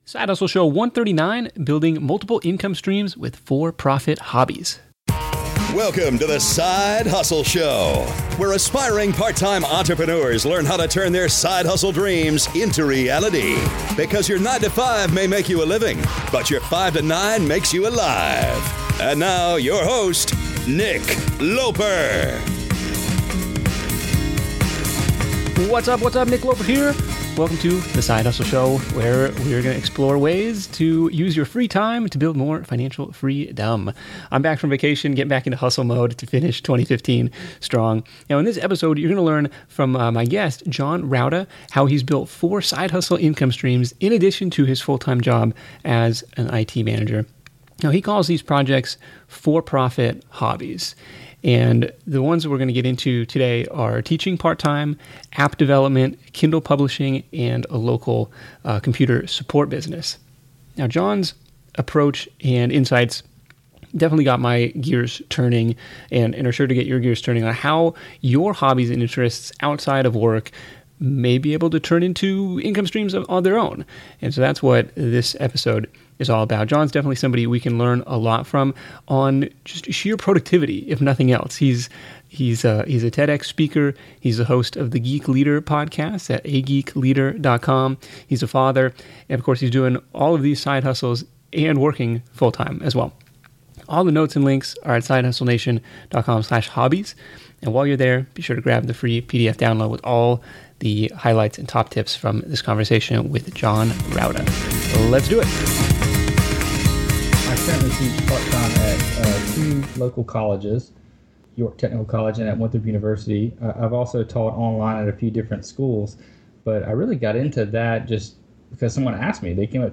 In fact, we recorded this session from his office at work!